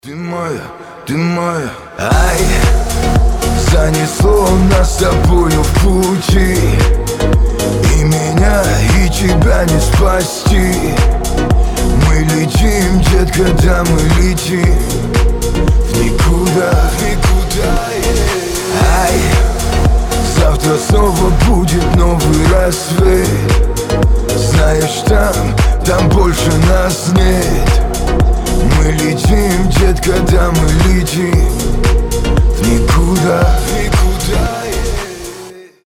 • Качество: 320, Stereo
поп
мужской вокал